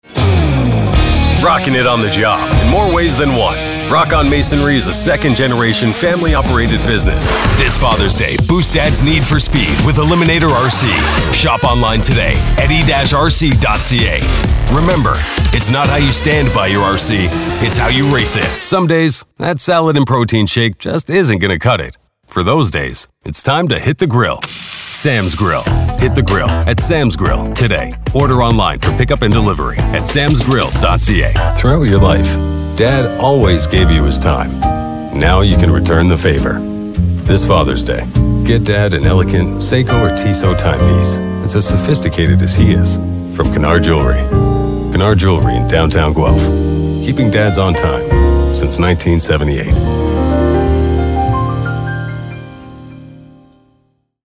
Adult, Mature Adult
• Accent: North American English, some Canadian French
• Voice Descriptions: Deep, booming, authoritative, announcer, warm & friendly, sexy
canadian | natural